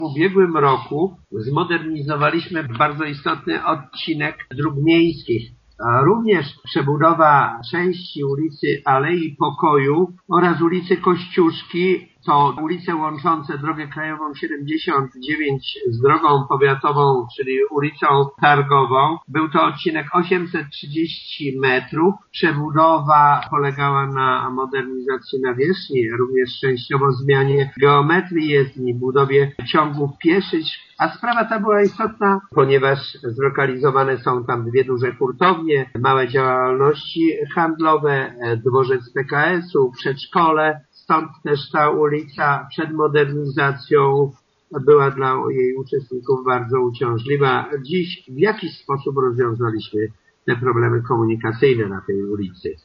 To już druga „schetynówka” w Zwoleniu – przypomina burmistrz Kabus: